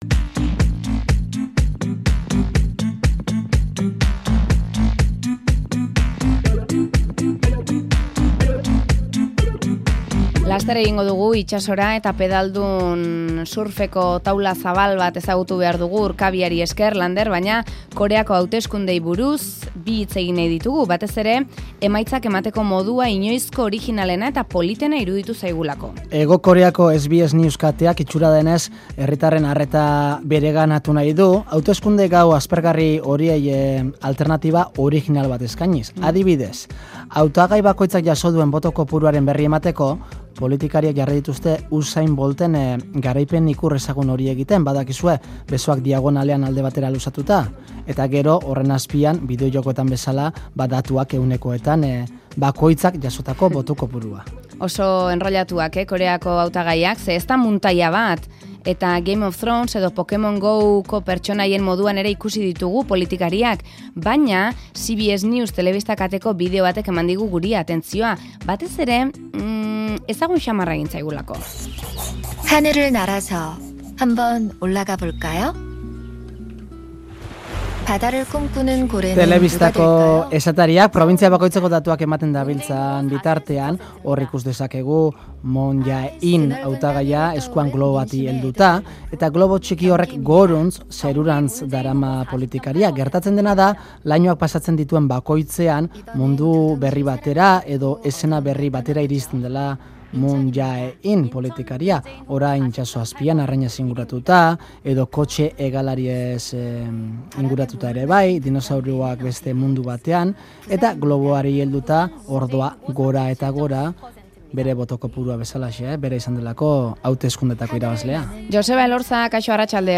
Artistarekin hitz egin dugu!